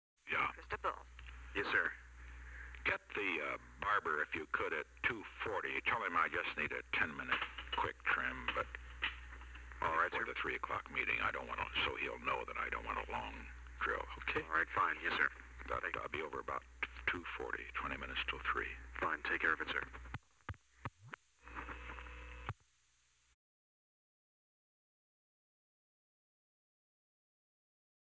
Location: White House Telephone